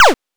8 bits Elements
laser_shot_9.wav